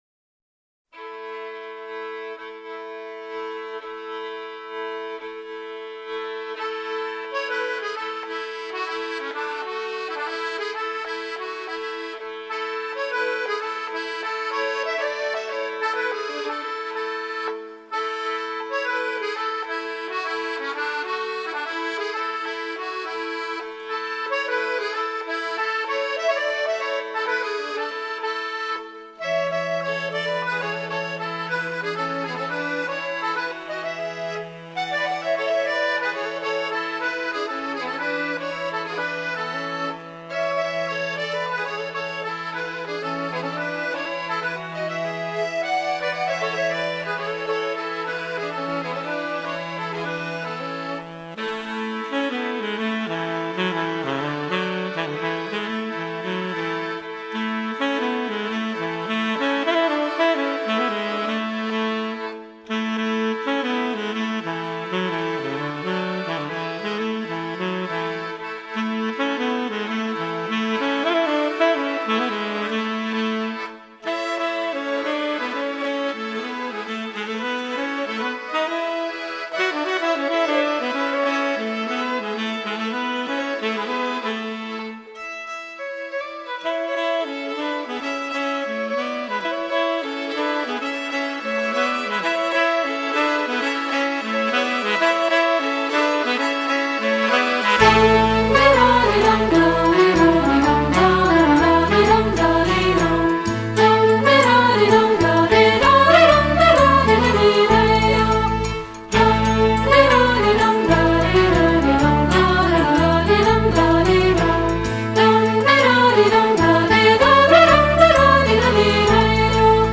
融合古典、摇滚、北欧乡村风、印度民族风、苏格兰曲风、柔情女声、吟游诗歌..等多元乐风
录音定位清楚、音质细腻清晰、音像深度及宽度精采可期
透过人声、小提琴、萨克斯风、双簧管、贝斯、吉他、钢琴、打击乐器、印度笛、竖笛、扬琴、